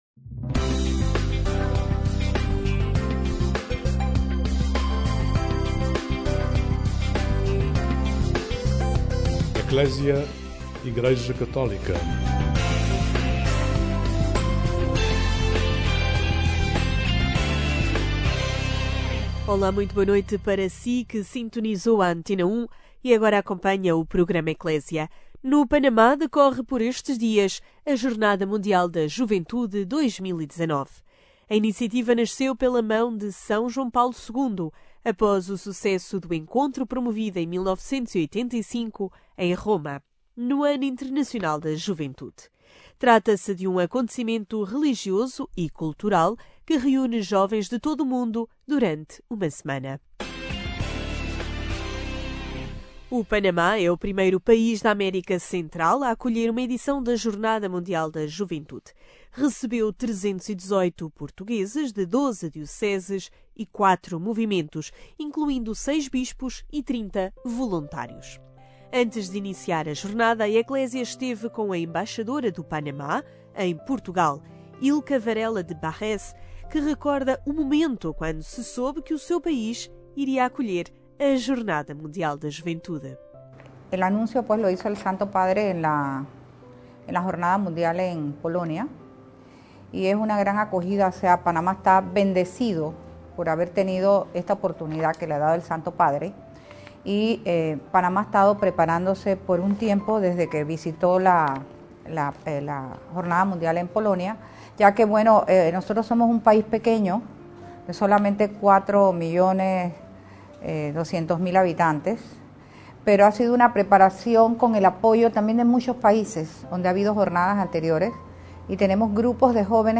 A Embaixadora do Panamá em Portugal, Ilka de Barés, falou com a Ecclesia dias antes da Jornada Mundial da Juventude, no Panamá 2019.